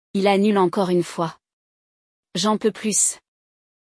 A pronúncia aproximada é jã pô plü, com um som nasal no “j’en”.